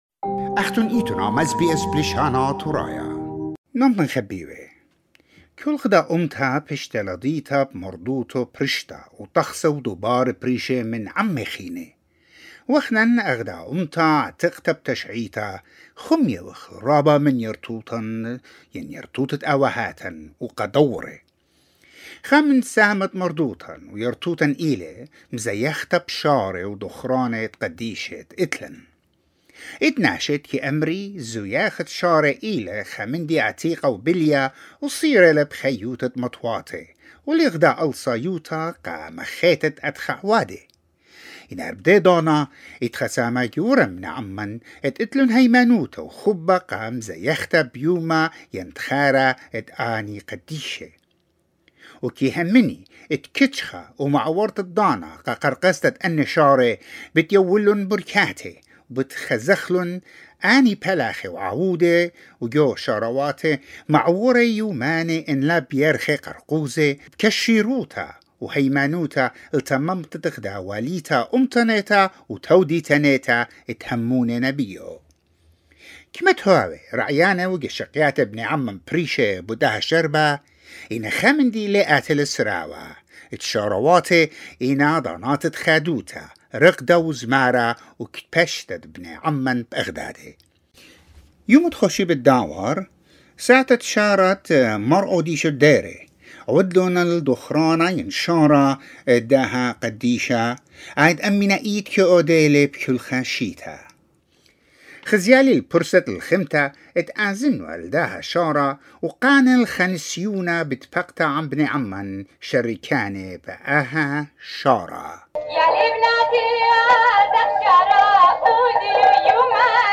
It is held for the remembrance of Saint Mar Audisho. SBS Assyrian went to the festival which was held in Sydney on Sunday 18 April 2021.